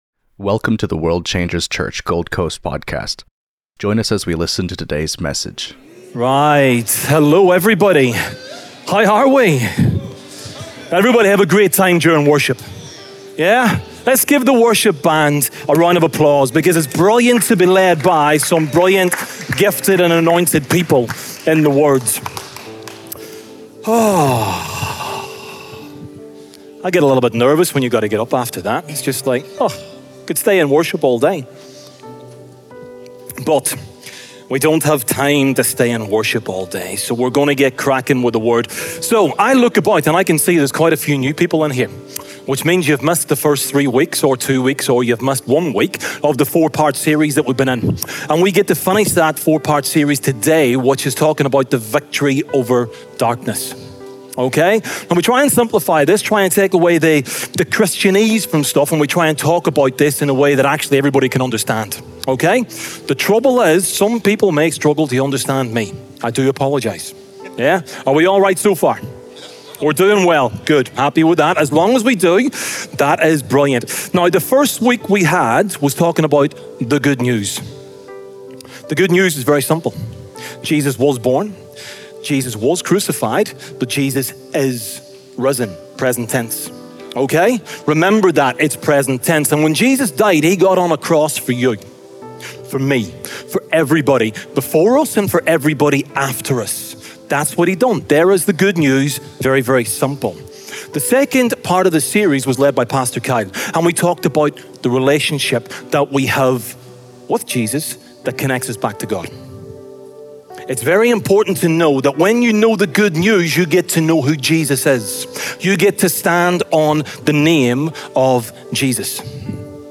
This message focuses on walking in the authority given through Christ, emphasizing that believers are not called to live in fear but in confidence. It highlights the difference between religion and a true relationship with Jesus, calling for a shift in mindset from powerless living to standing firm in spiritual identity. The sermon explores how authority is not earned but received through faith, requiring surrender and boldness.